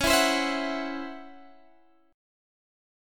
DbmM7bb5 chord